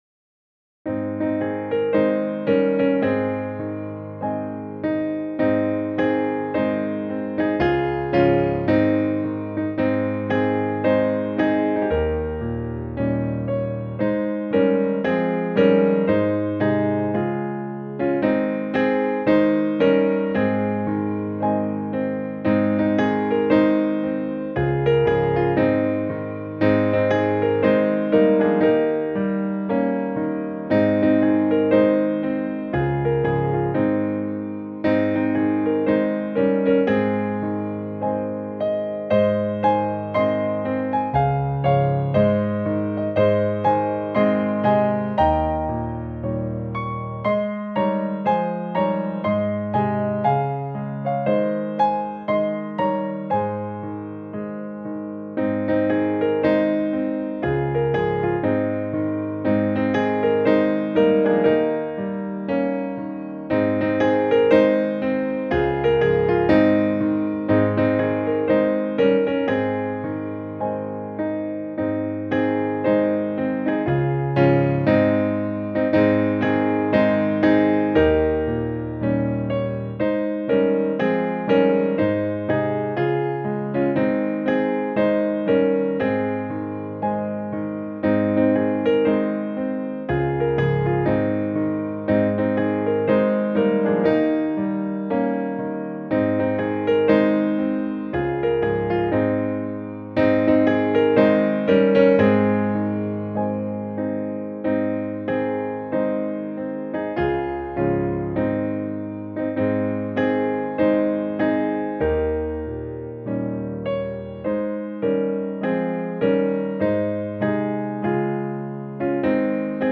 Piano
Music by: USA campmeeting melody;